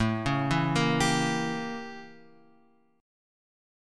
A7b9 chord {5 4 2 3 x 3} chord